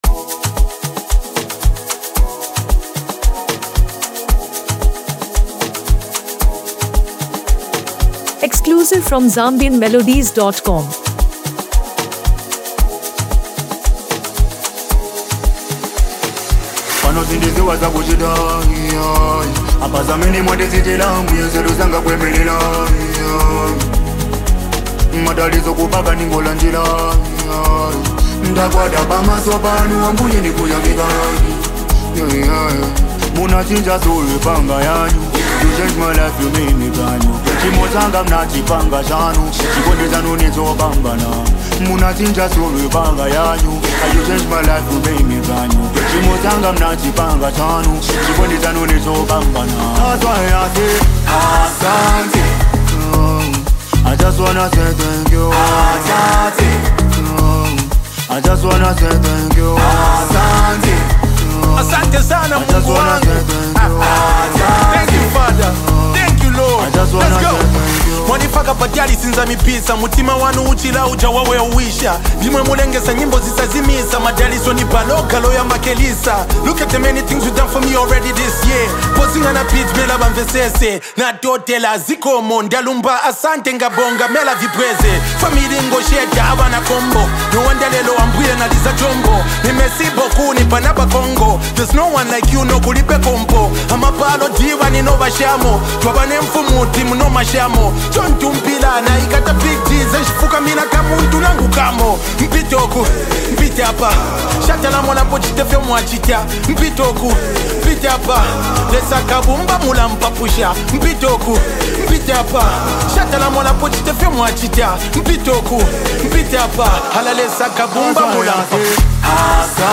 uplifting message and smooth melody.